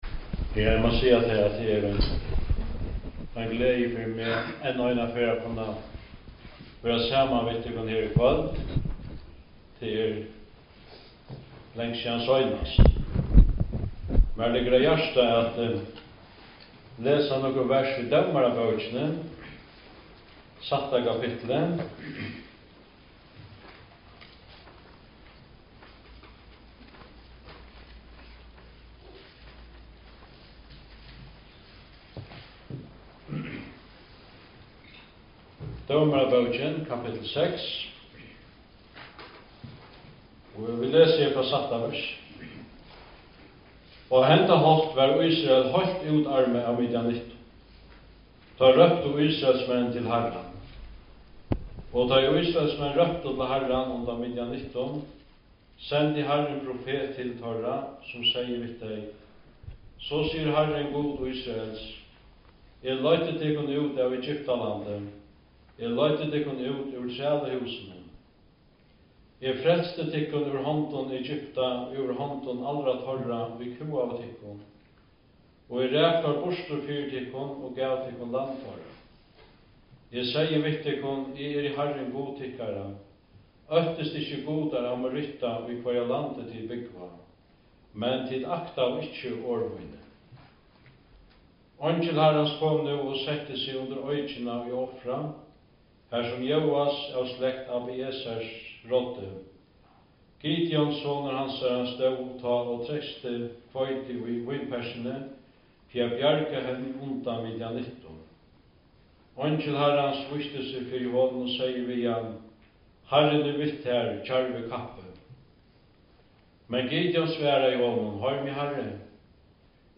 Talur (Fø)